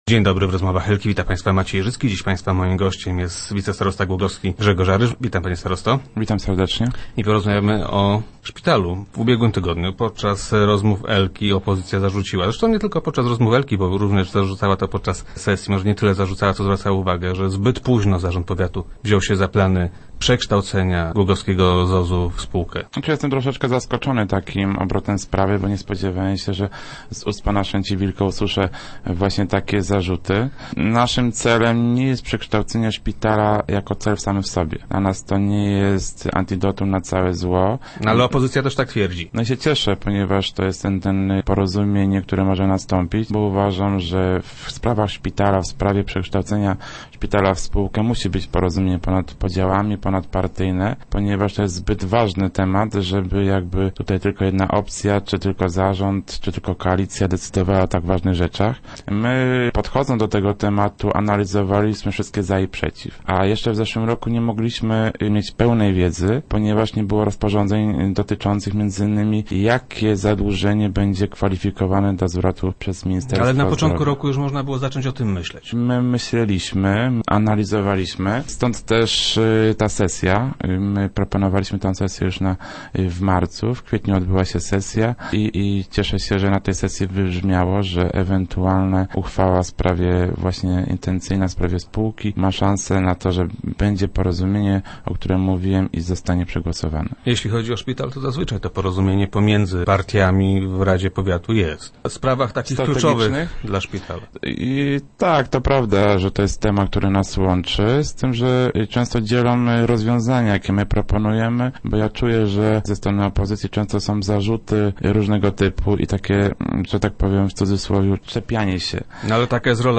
Członkowie zarządu zapewniają jednak, że czasu na zmiany nie zabraknie. Gościem Rozmów Elki był wicestarosta Grzegorz Aryż.